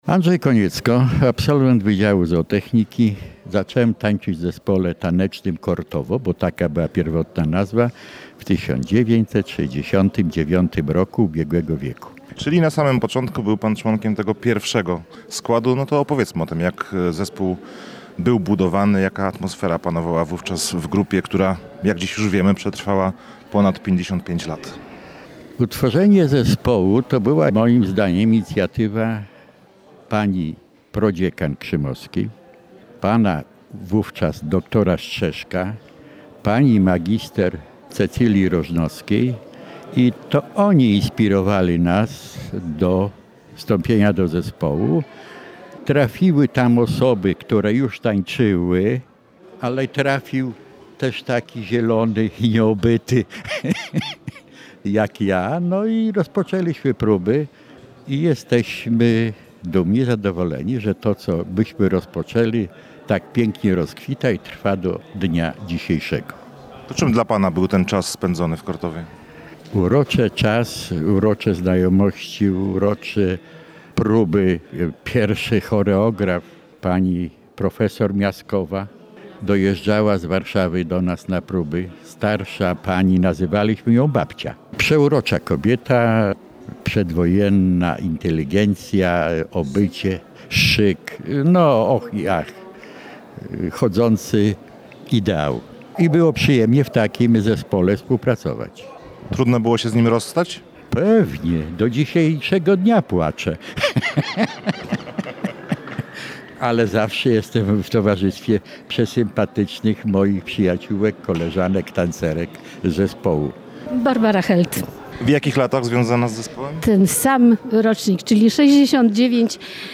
Trzy galowe koncerty, wystawa fotograficzna i bal – tak Zespół Pieśni i Tańca „Kortowo” świętował swoje 55-lecie. W czwartek, piątek i sobotę sala Centrum Konferencyjnego Uniwersytetu Warmińsko-Mazurskiego w Olsztynie wypełniła się do ostatniego miejsca.